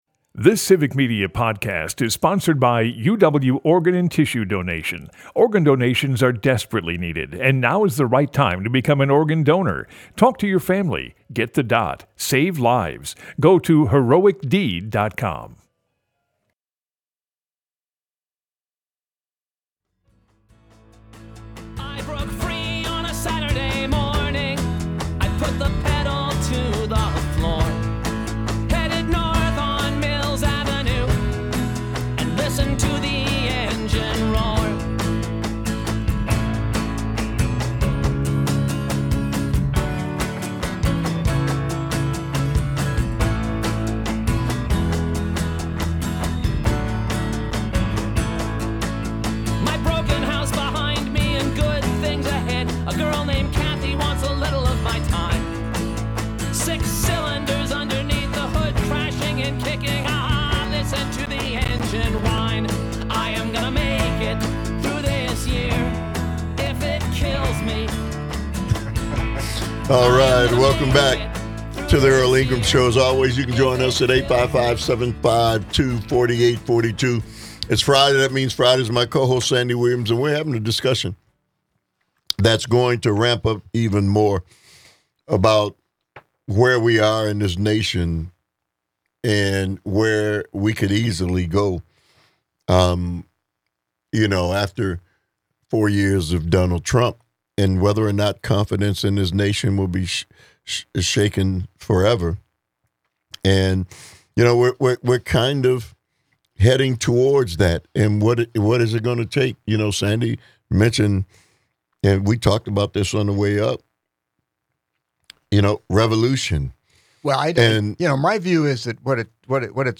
Broadcasts live 8 - 10am weekdays across Wisconsin.